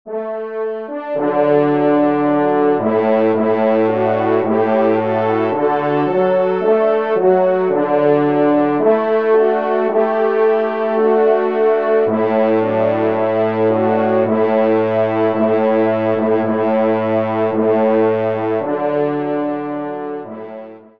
Genre : Musique Religieuse pour Trois Trompes ou Cors
Pupitre 3° Trompe